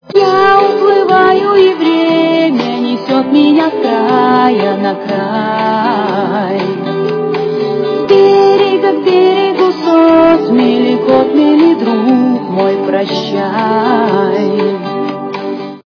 » Реалтоны » фильмы и телепередач » Музыка из к-ф
качество понижено и присутствуют гудки.